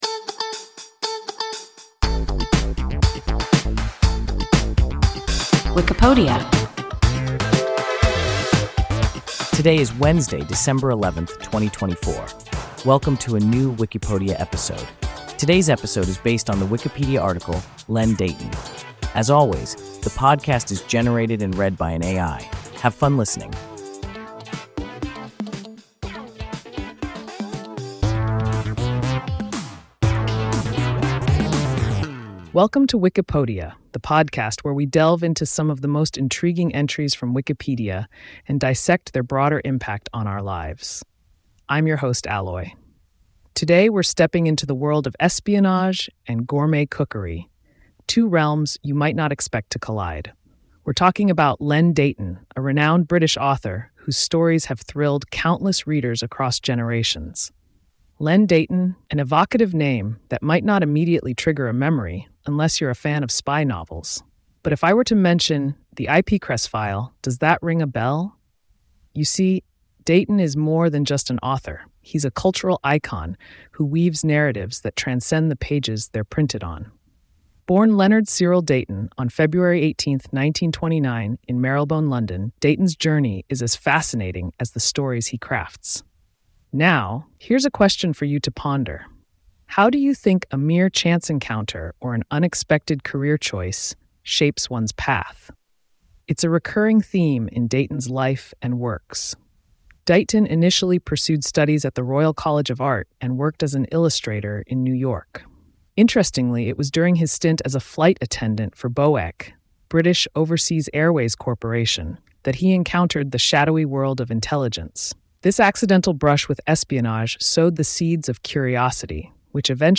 Len Deighton – WIKIPODIA – ein KI Podcast